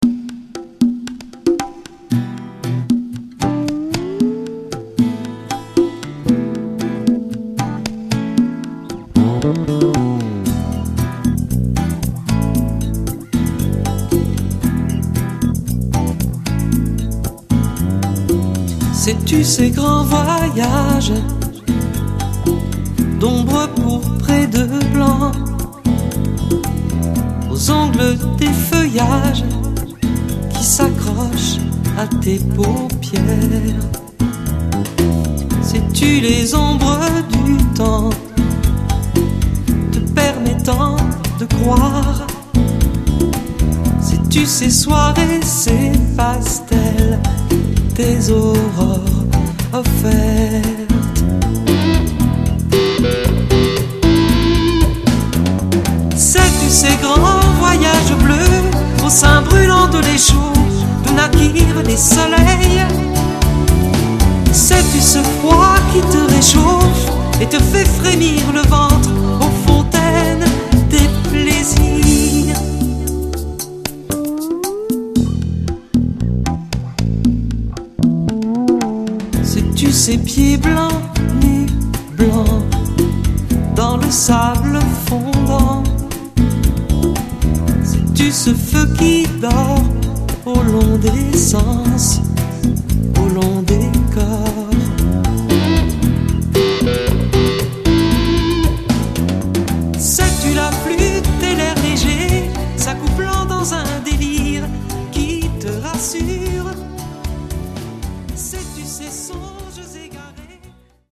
au studio l'hiver 2005